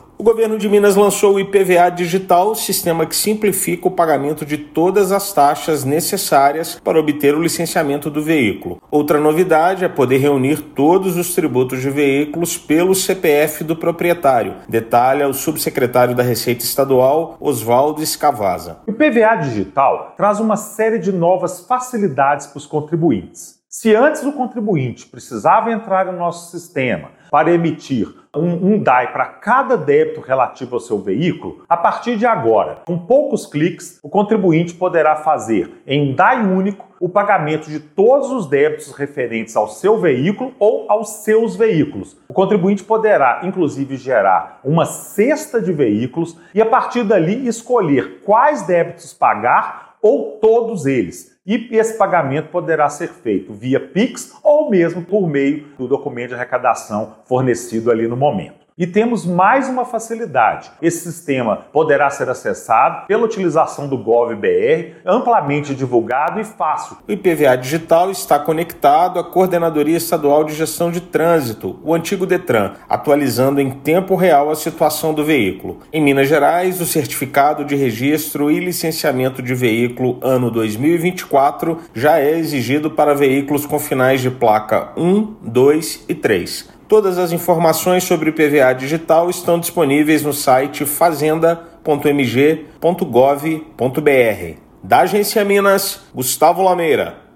[RÁDIO] Governo de Minas lança sistema que facilita pagamento do IPVA
IPVA Digital deixa acesso mais ágil e com rede de dados conectada em tempo real à Coordenadoria de Trânsito. Ouça matéria de rádio.